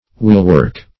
Wheelwork \Wheel"work`\, n. (Mach.)